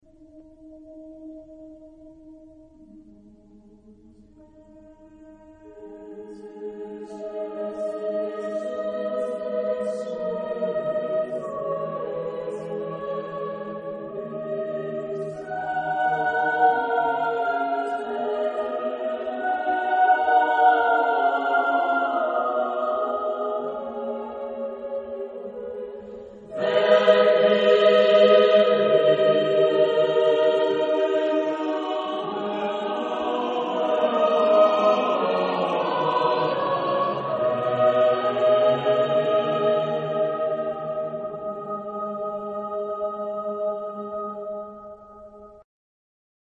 Tipo de formación coral: SATB  (4 voces Coro mixto )